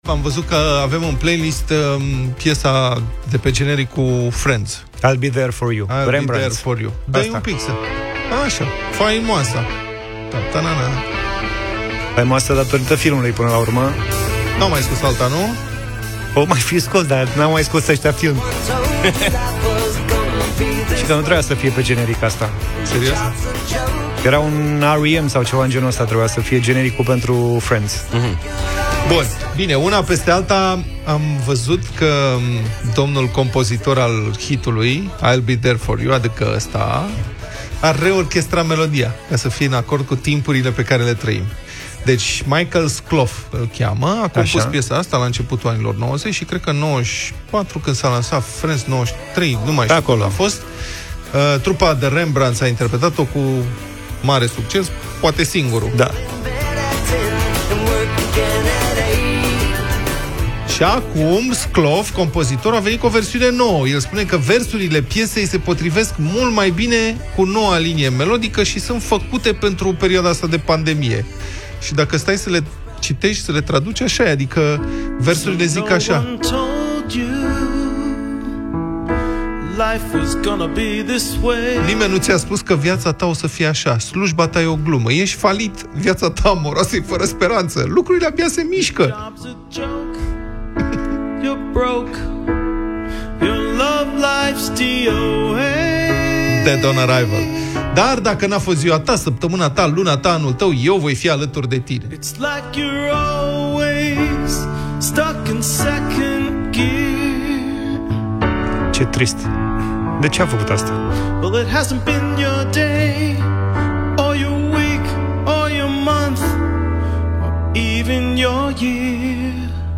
Acum, Michael Skloff a venit cu o versiune nouă, mai melancolică și adaptată vremurilor.
au ascultat cele două versiuni ale cântecului în Deșteptarea.